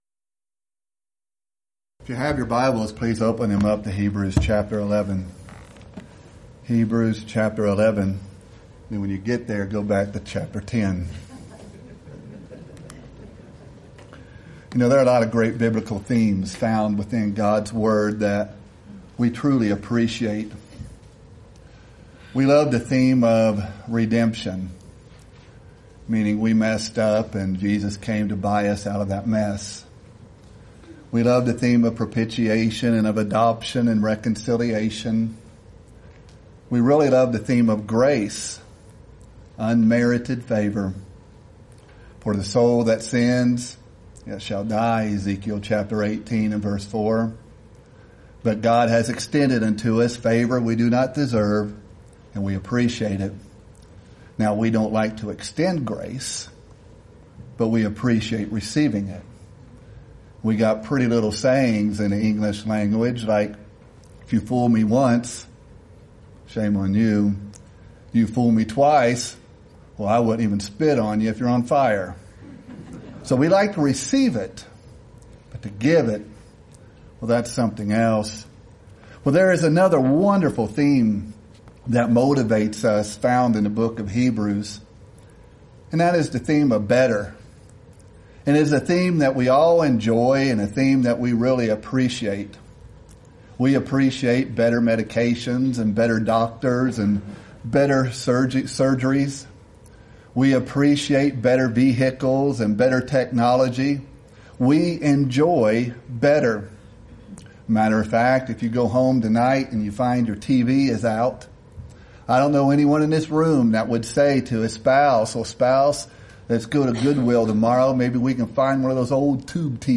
Event: 26th Annual Lubbock Lectures
lecture